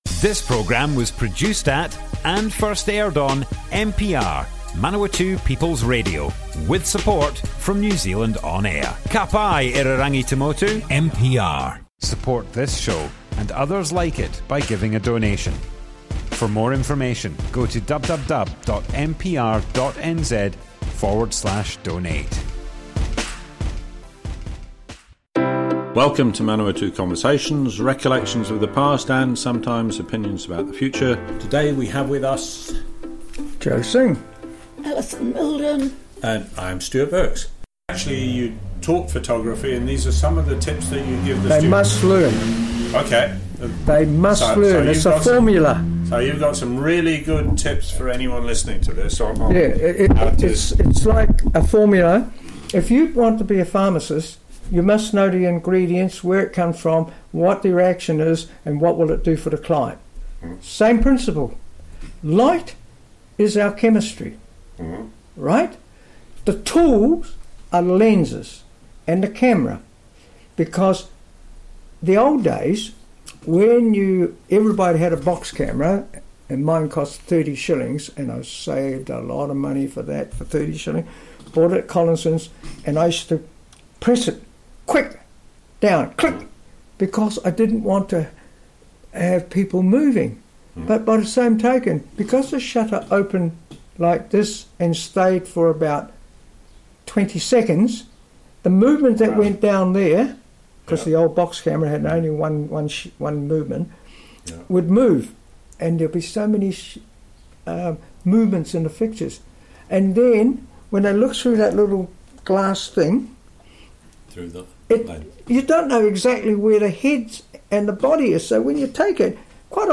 Manawatu Conversations More Info → Description Broadcast on Manawatu People's Radio, 9th March 2021.
oral history